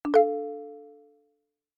trade-notif.c59e24fdfbdf7b64.mp3